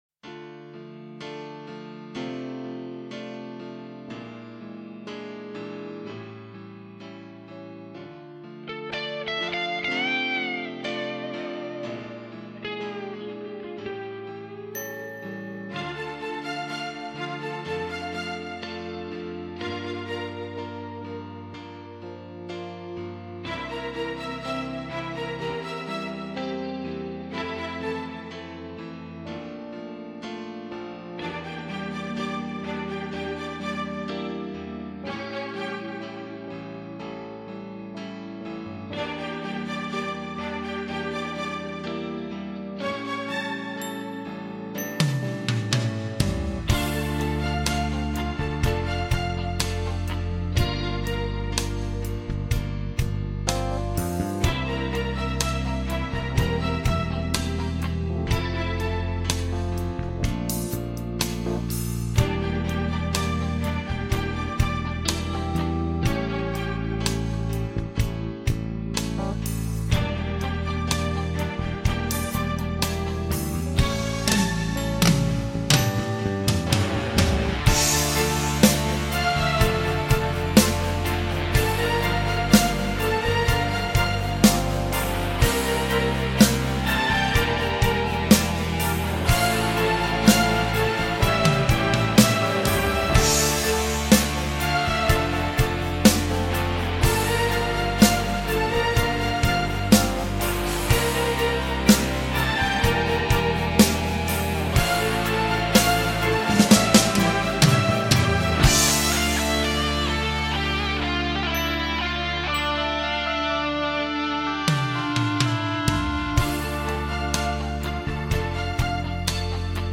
krasivaa_muzyka_bez_slov_5musicme_5music_me.mp3